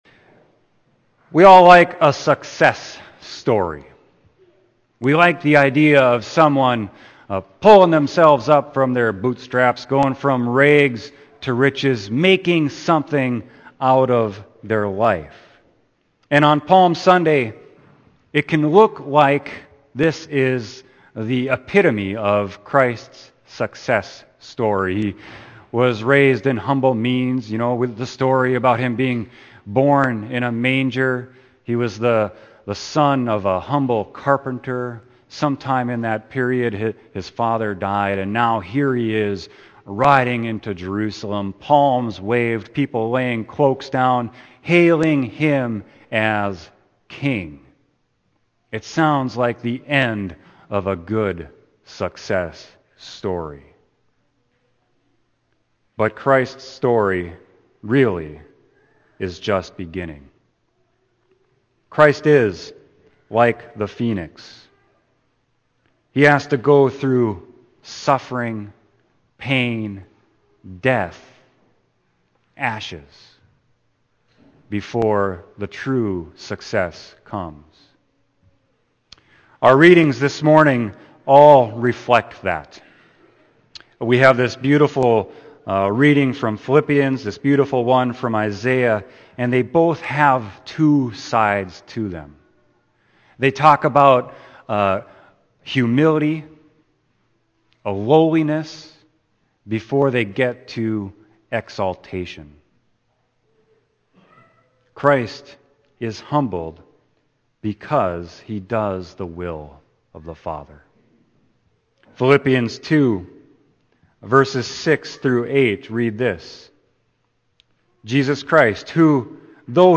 Sermon: Palm Sunday 2016